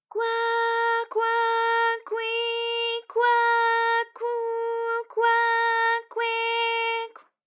ALYS-DB-001-JPN - First Japanese UTAU vocal library of ALYS.
kwa_kwa_kwi_kwa_kwu_kwa_kwe_kw.wav